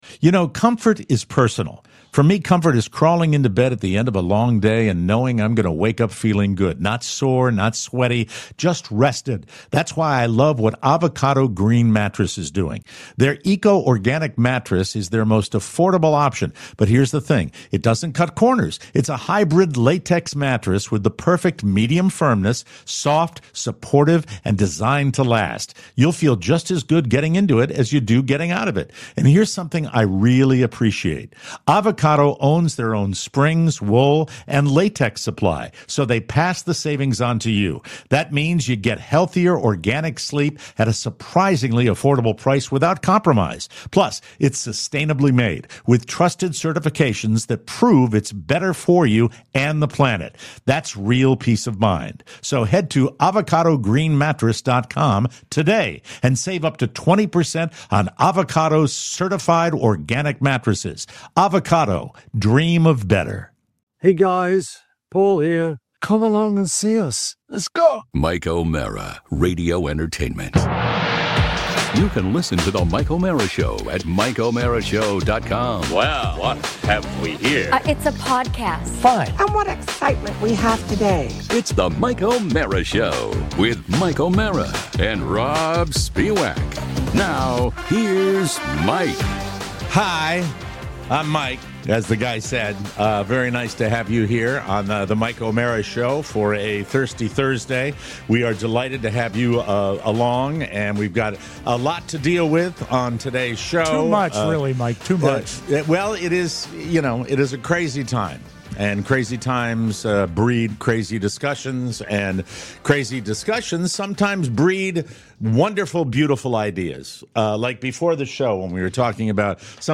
Water and coffee and whatnot... and a quick appearance from NBC's Keith Morrison.